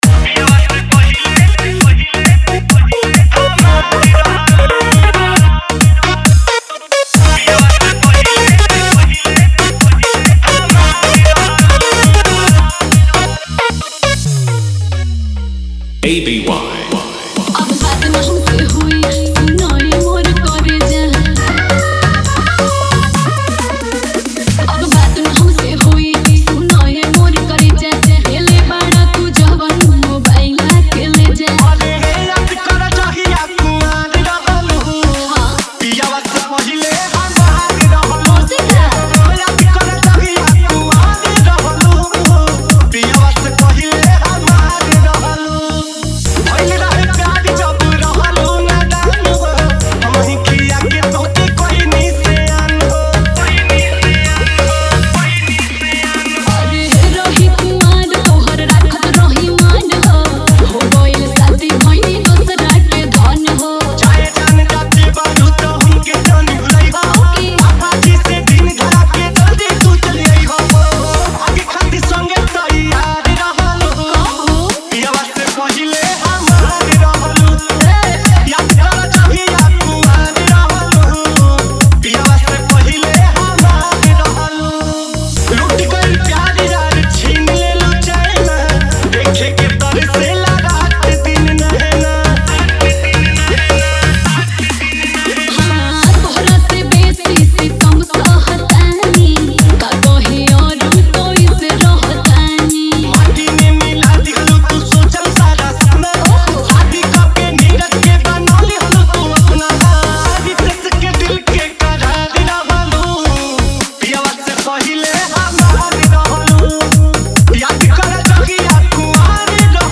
DJ Dialogue Intro Script – U.P.70 Bass Mix Style
[Drop FX: vocal cut + 808 BASS roll]
This is an original remix